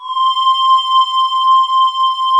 Index of /90_sSampleCDs/USB Soundscan vol.28 - Choir Acoustic & Synth [AKAI] 1CD/Partition D/26-VOCOSYNES